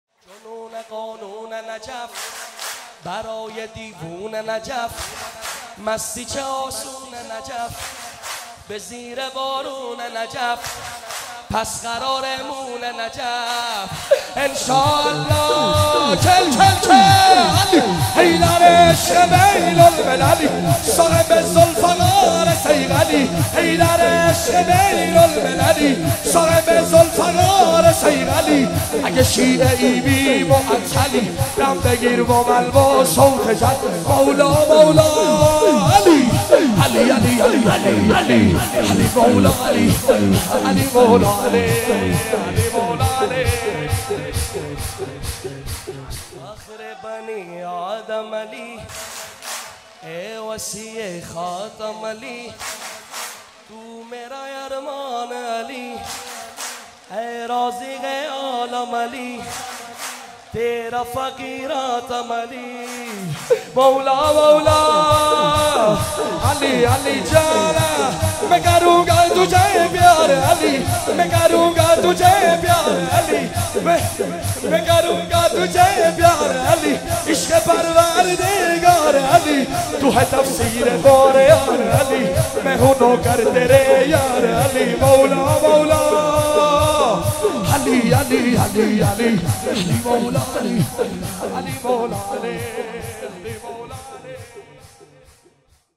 ولادت حضرت معصومه (س) هیئت مکتب الزهرا(س) قم 24 تیر 97